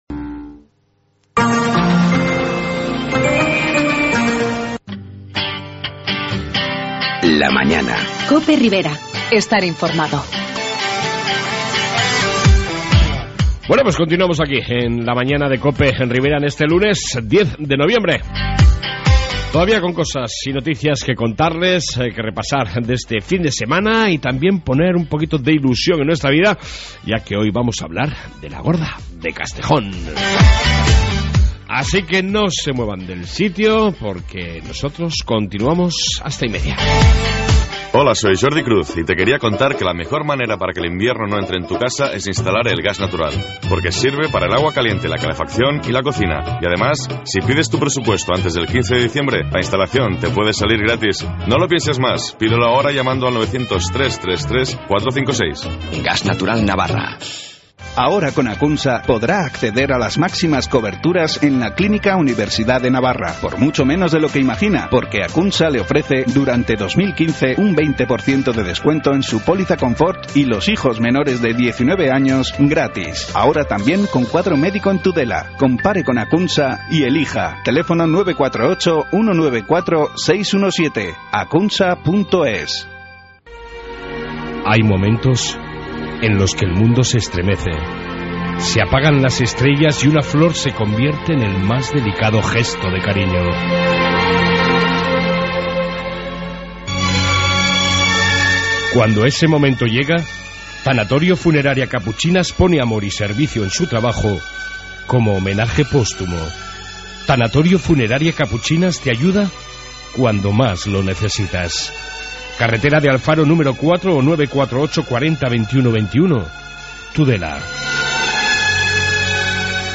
AUDIO: Informativo Ribero y entrevista sobre la Cesta "La Gorda de Castejón"!